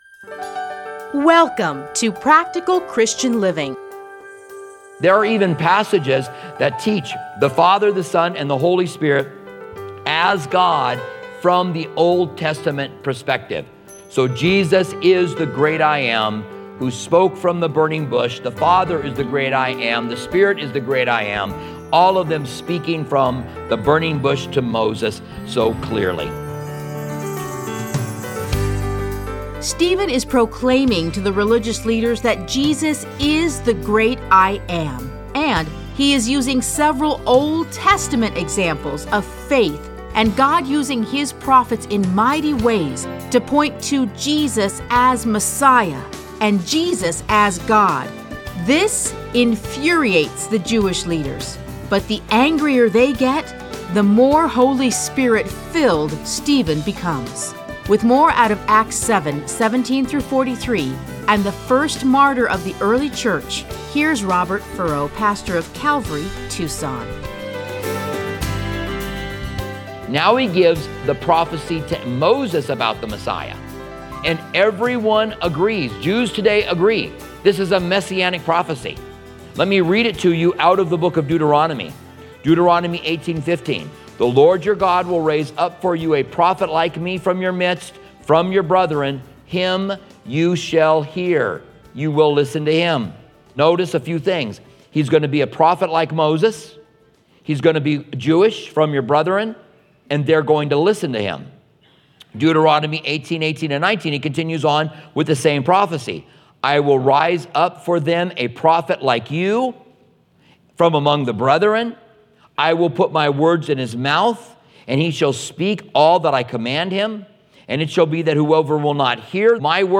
Listen to a teaching from Acts 7:17-43.